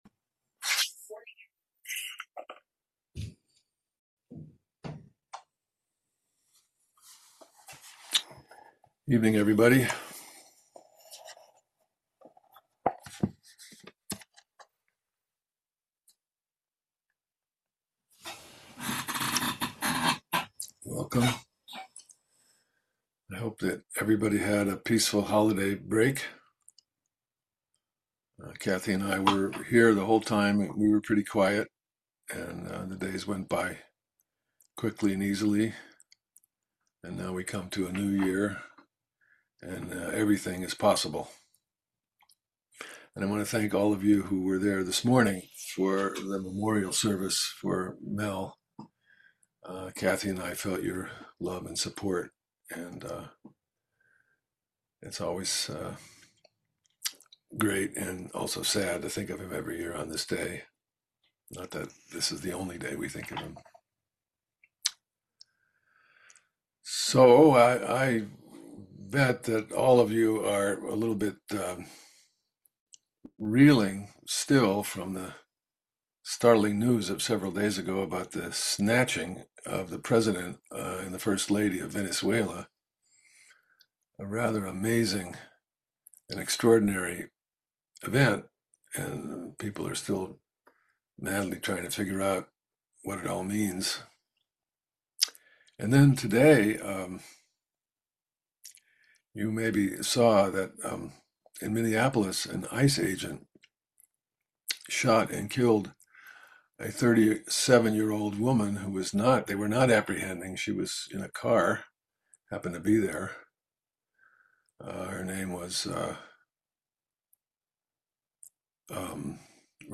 Dharma Talks from the Everyday Zen Foundation